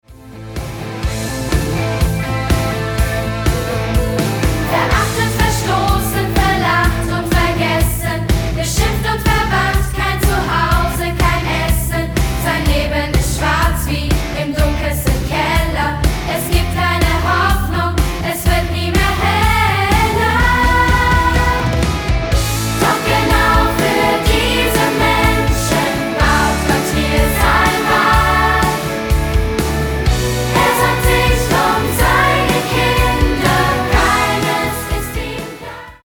Musical-CD